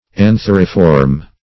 Search Result for " antheriform" : The Collaborative International Dictionary of English v.0.48: Antheriform \An*ther"i*form\, a. [Anther + -form.] Shaped like an anther; anther-shaped.